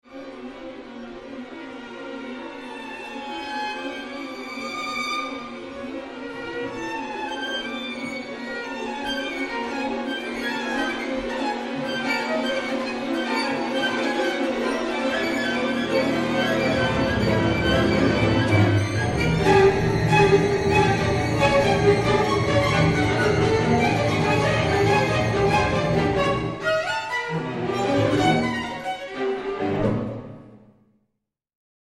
für Violine, Viola u. Streicher (1993)